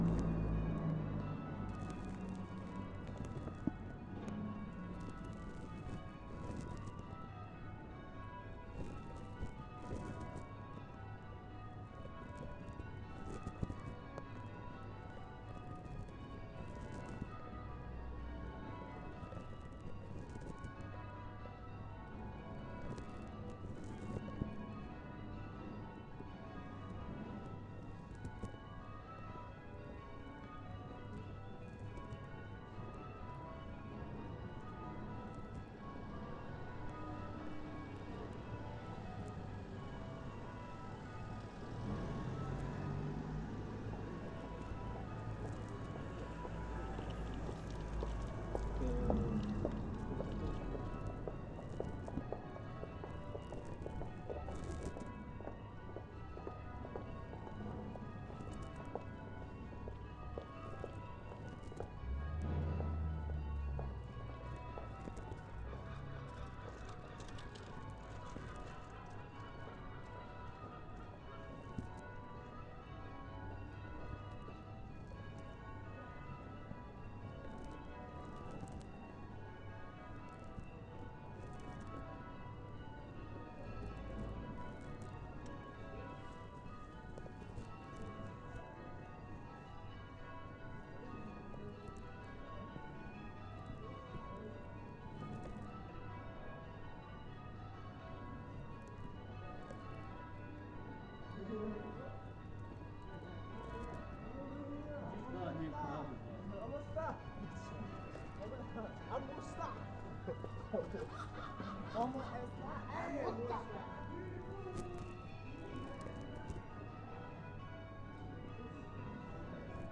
St George's Day Bells 2. Includes gulls and traffic